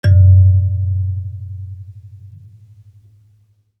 kalimba_bass-F#1-mf.wav